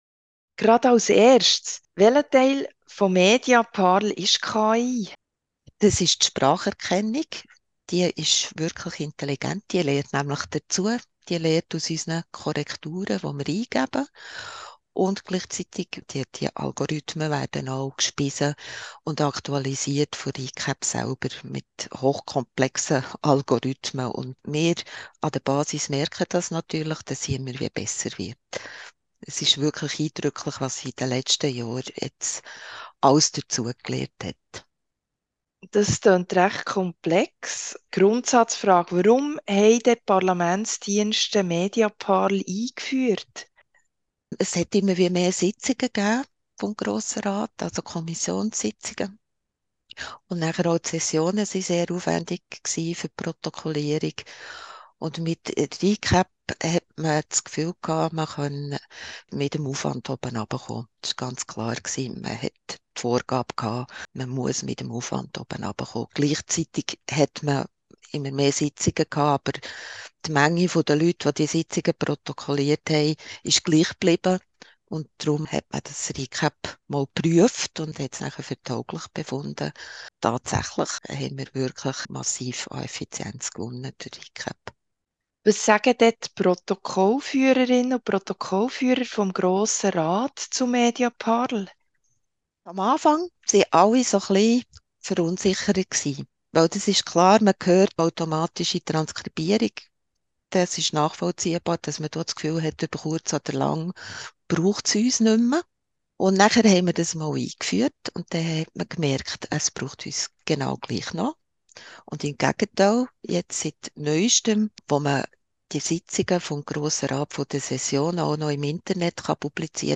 KI_GR_Interview.mp3